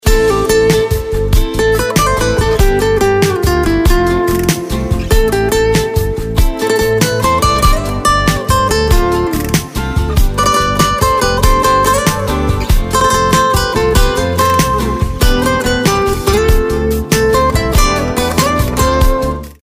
زنگ تلفن همراه شاد